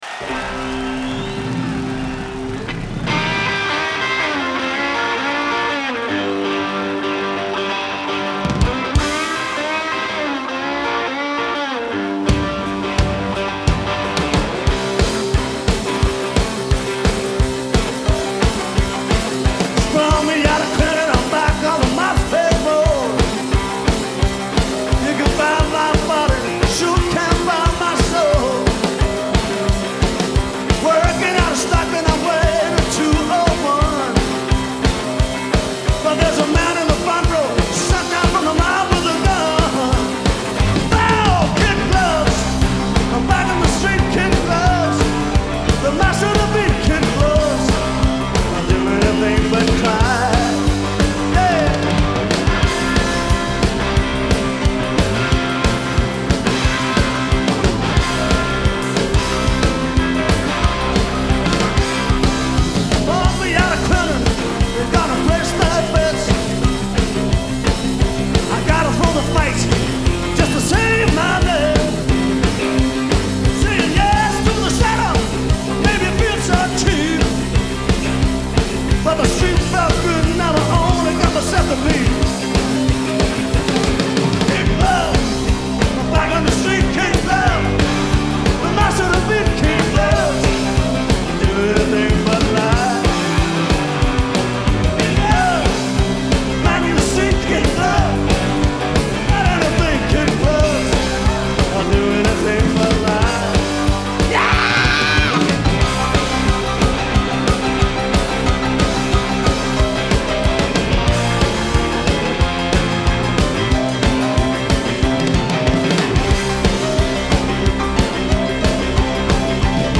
Genre : Blues
Live At The Town & Country Club, London, UK